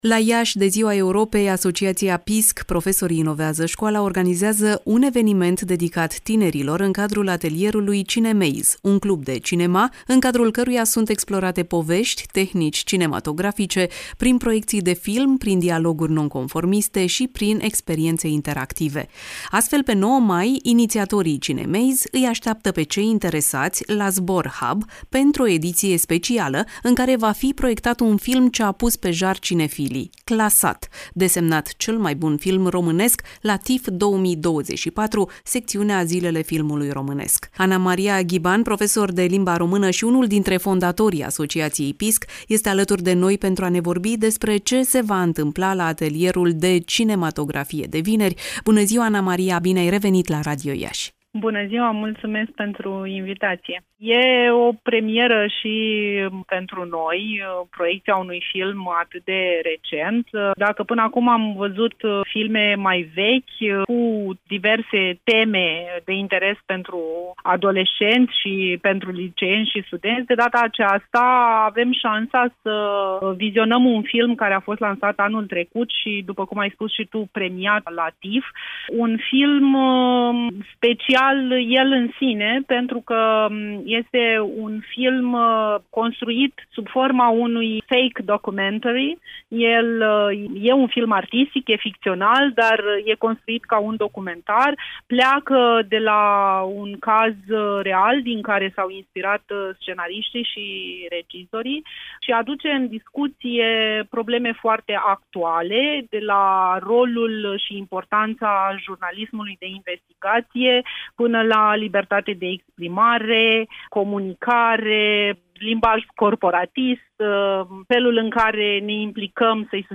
Varianta audio a interviului: